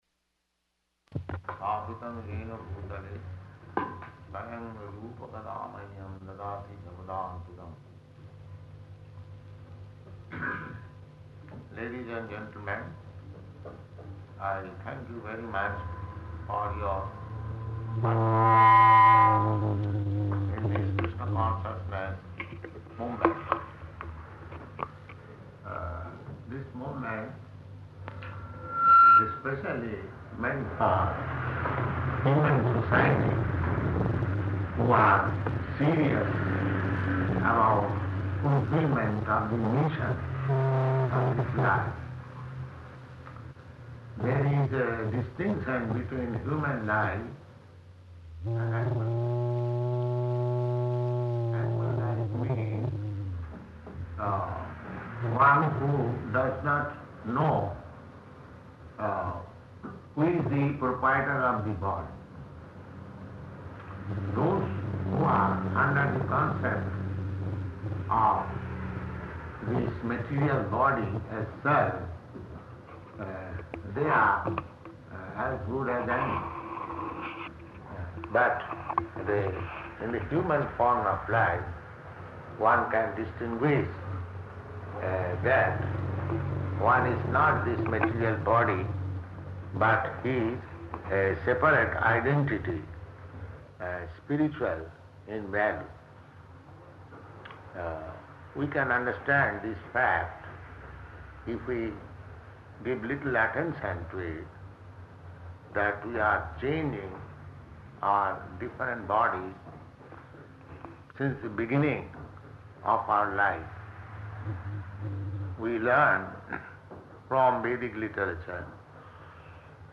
Lecture
Type: Lectures and Addresses
Location: Paris Audio file: 710626LE-PARIS.mp3